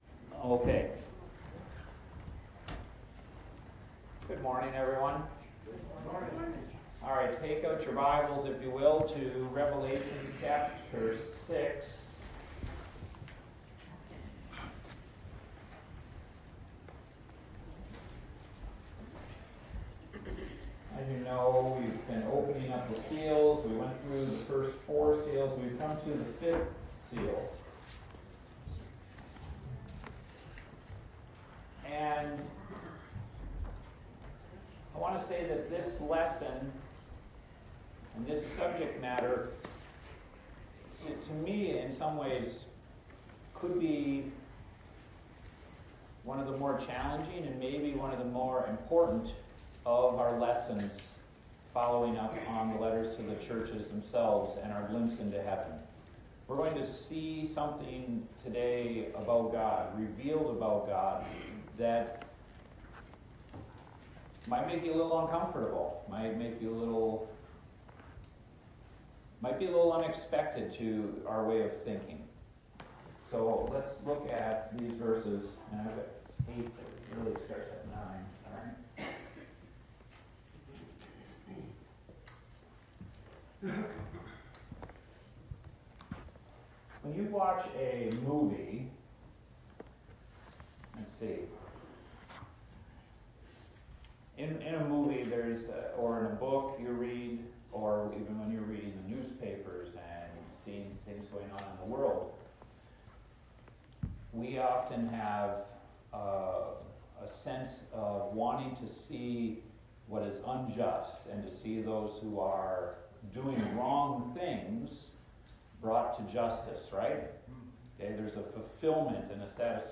Adult Sunday School A Study in Revelation